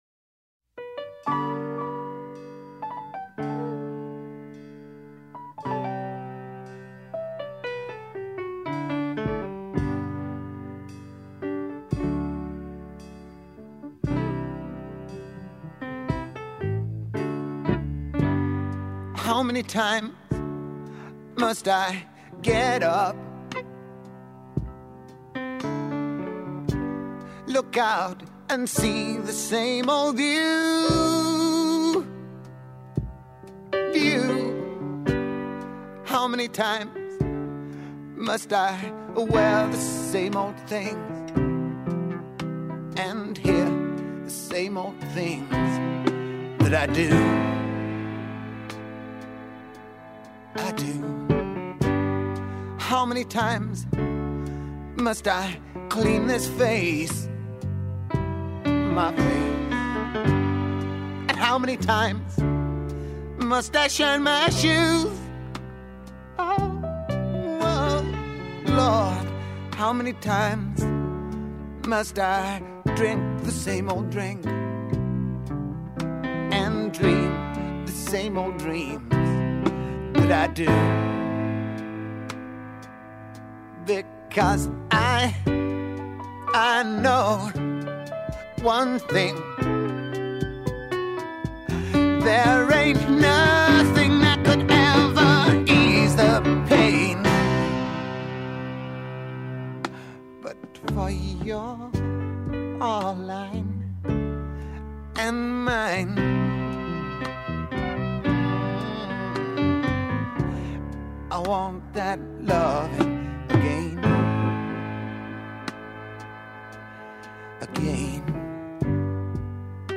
takes on an R & B feel
haunting and soulful